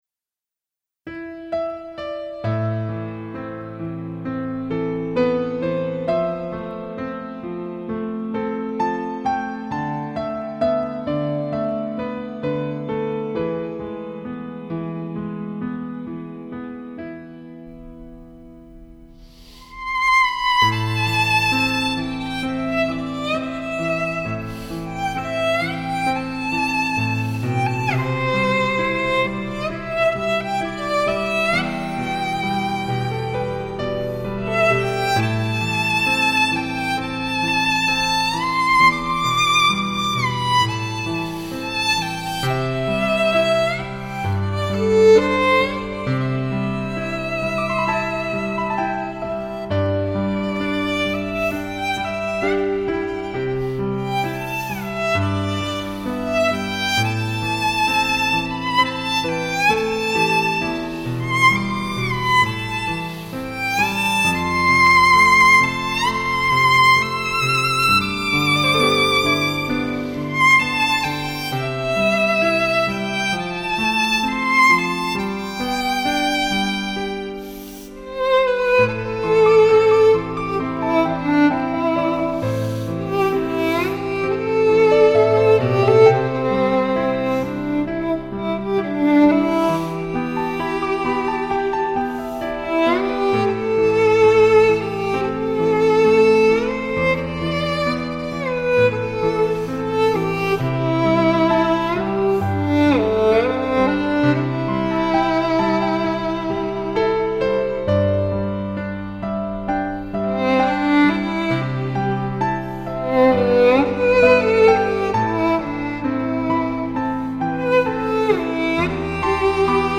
曲子听来比文字更忧伤。